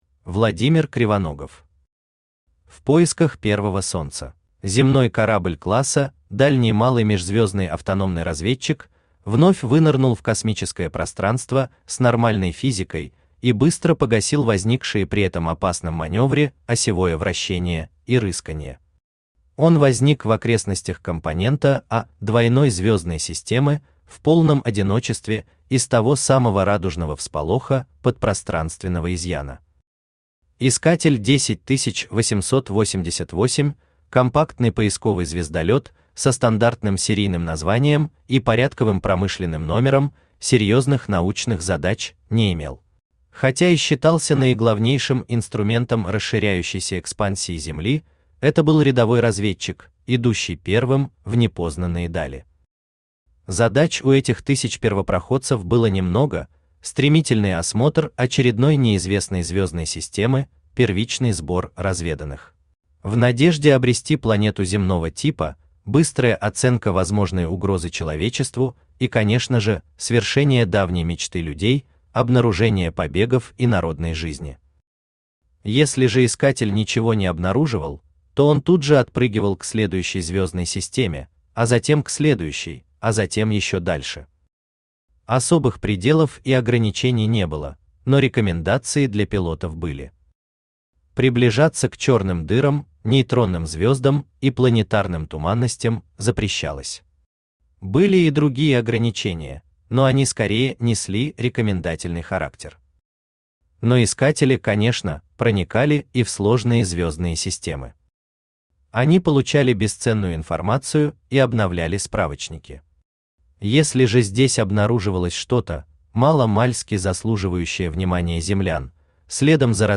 Аудиокнига В поисках первого Солнца | Библиотека аудиокниг
Aудиокнига В поисках первого Солнца Автор Владимир Кривоногов Читает аудиокнигу Авточтец ЛитРес.